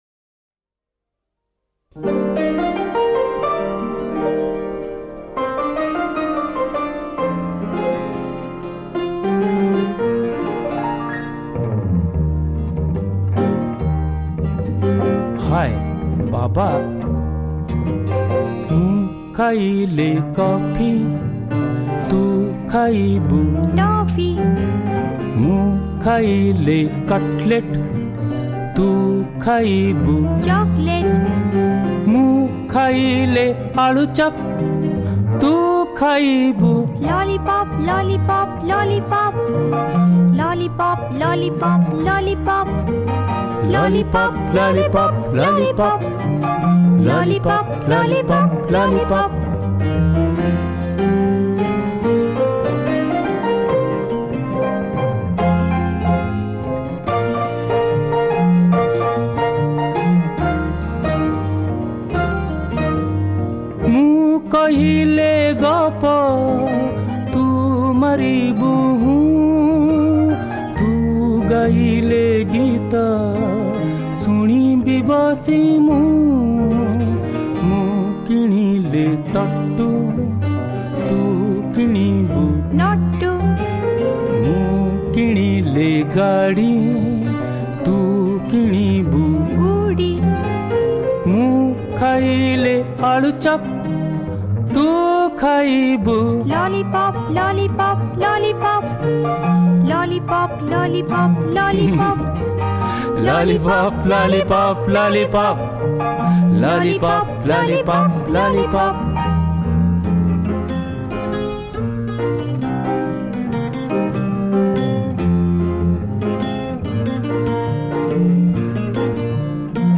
Oriya Modern Songs